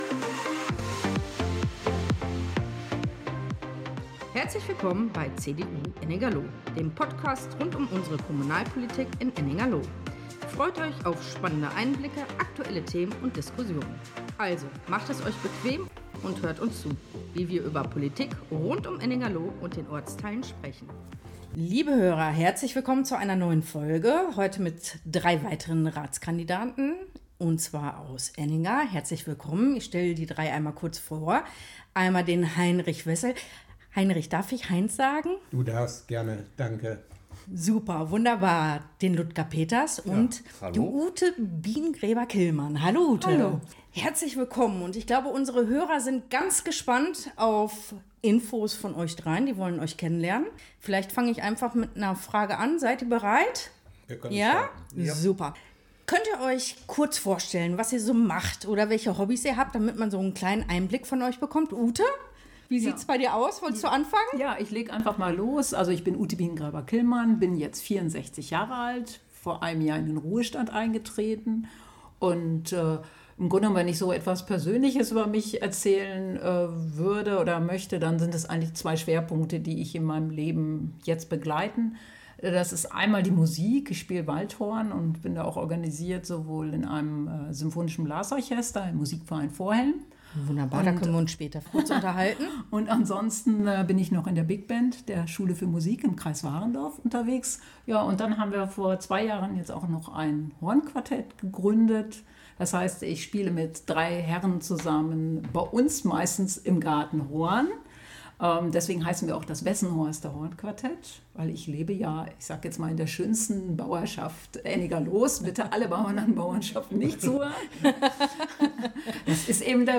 Lively-Instrumental Intro und Outro